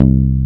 Index of /90_sSampleCDs/Roland - Rhythm Section/BS _Jazz Bass/BS _Jazz Basses